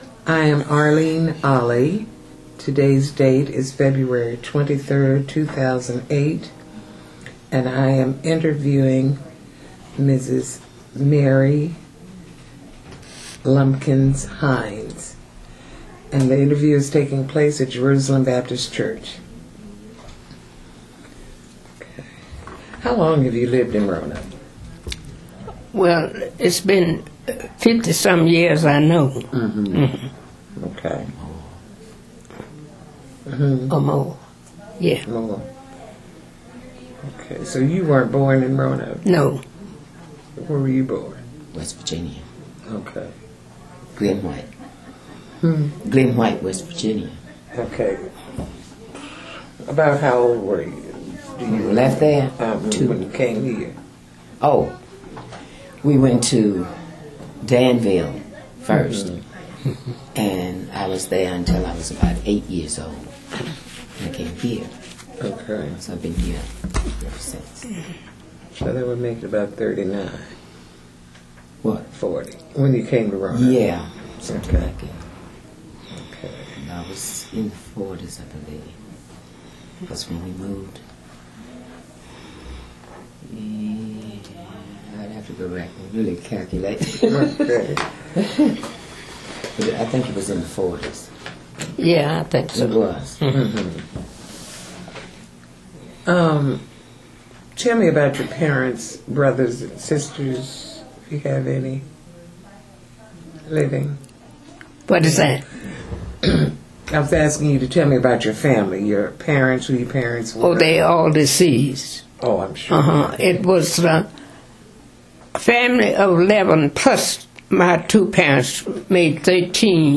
Neighborhood History Interview
Location: Jerusalem Baptist Church